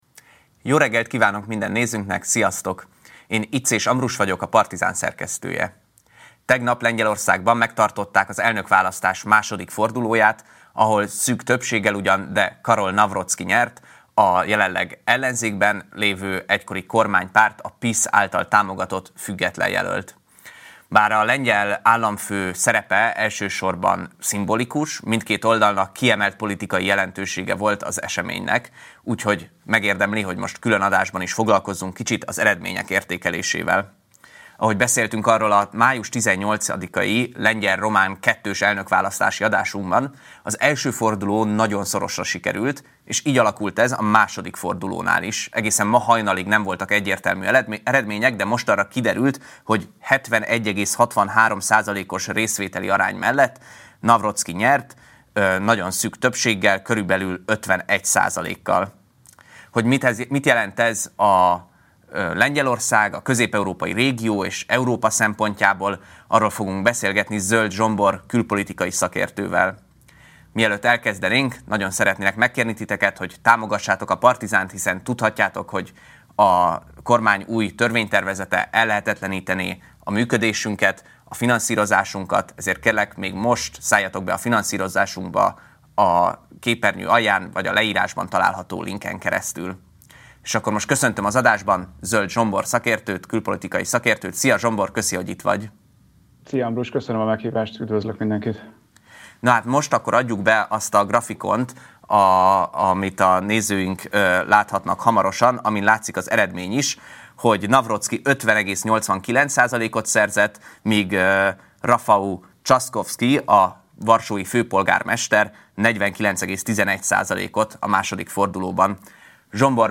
Utána Orbán Viktor 25. évértékelőjét közvetítjük a Várkert Bazárból.